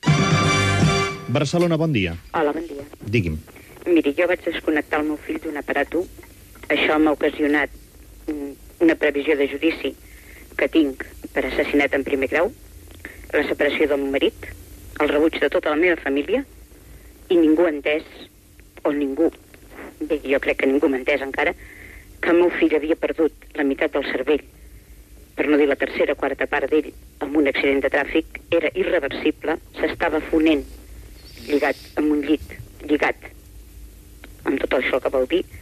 Testimoni d'una mare que havia practicat l'eutanàsia al seu fill.
Info-entreteniment